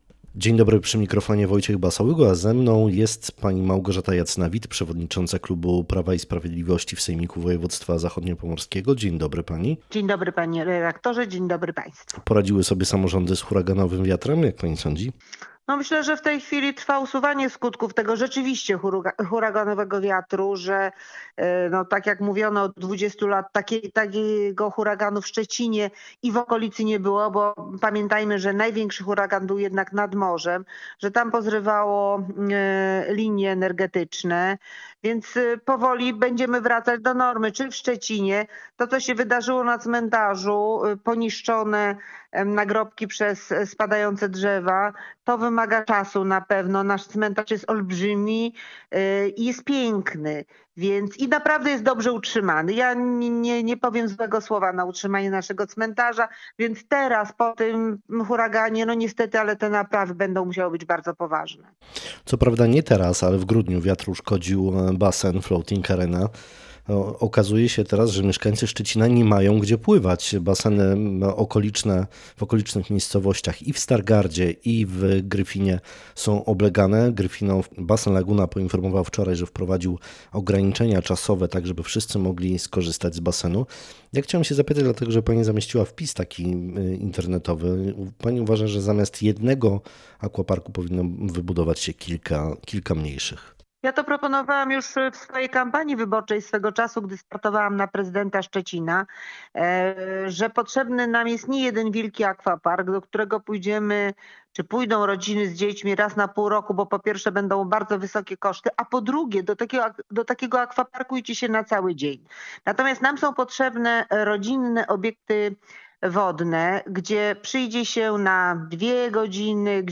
Naszym dzisiejszym gościem Rozmowy Dnia jest Małgorzata Jacyna-Witt, szefowa klubu PiS w Sejmiku Województwa Zachodniopomorskiego. Z okazji obniżenia VAT na ceny paliw, zapytaliśmy dokładnie o tę decyzję, która może spowodować, że problem inflacji będzie mniejszy.